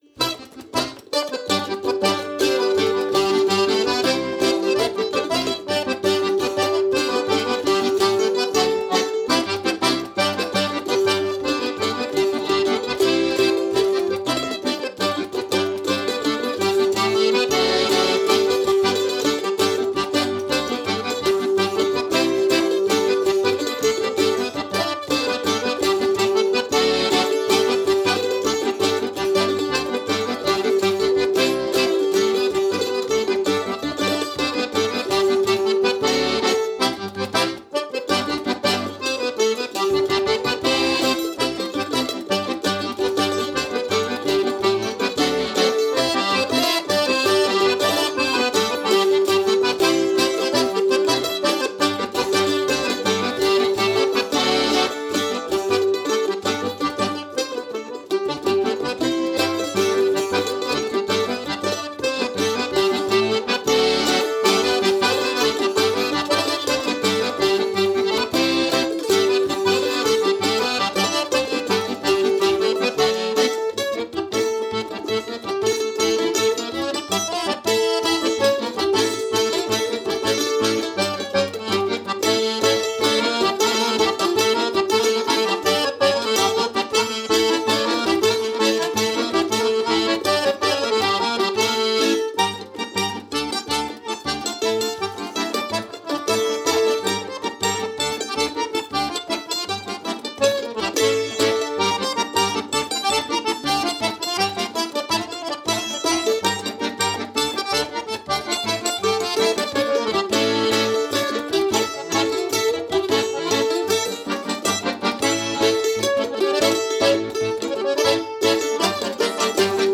Пишу басовый парт, быстро, качественно, недорого
Я играю на басу довольно давно, и хорошо изучил этот инструмент.